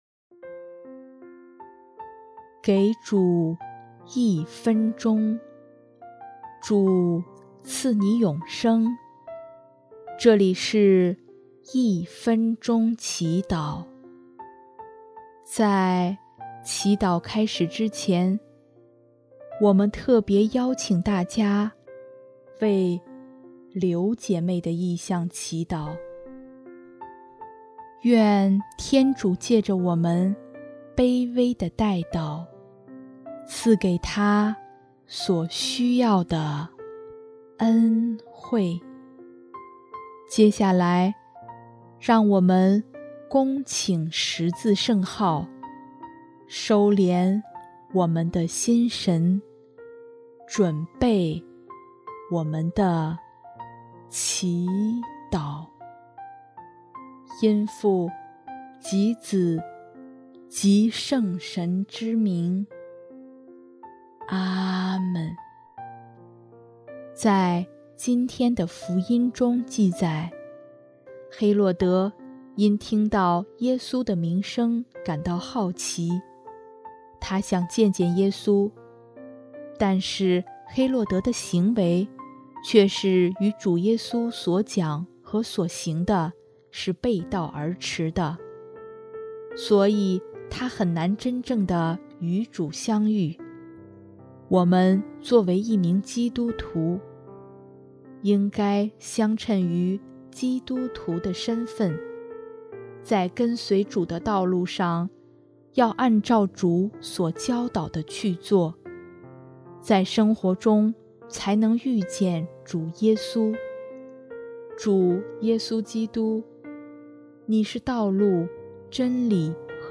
【一分钟祈祷】|9月26日 寻找生活的天主
音乐： 主日赞歌《生活的天主》